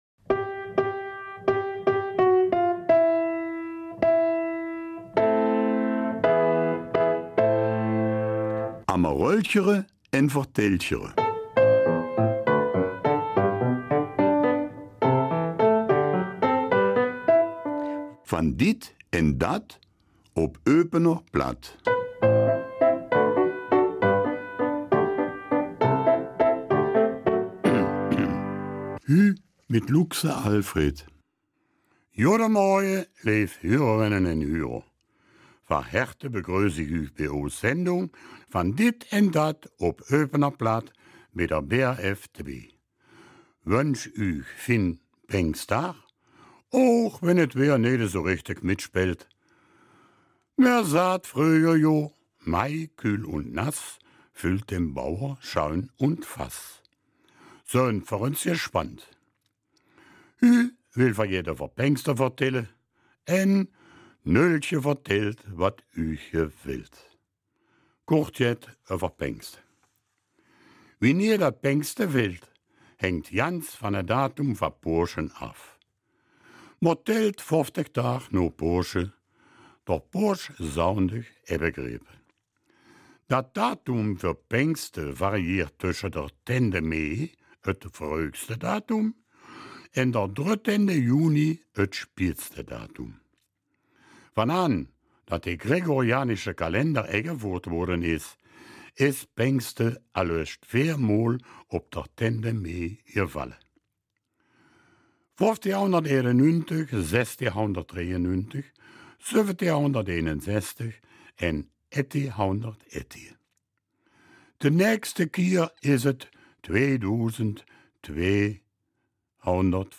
Eupener Mundart: 23. Mai